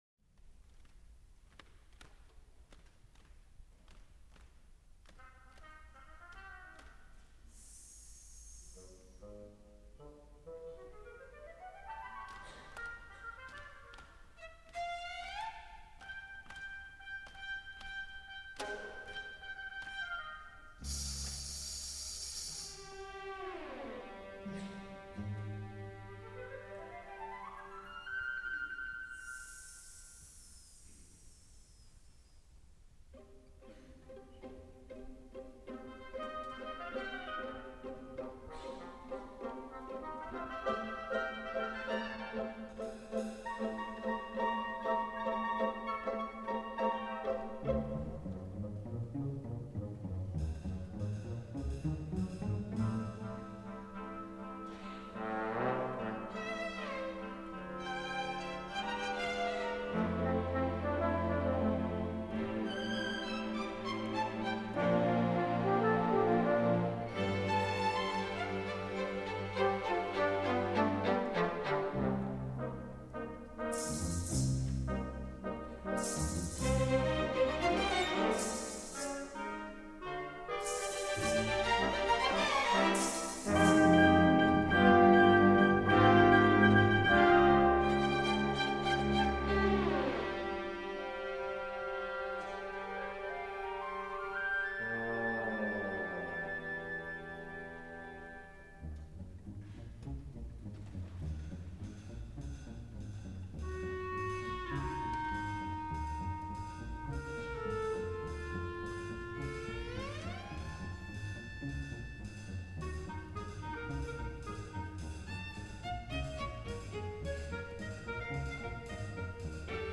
for Chamber Orchestra (1987)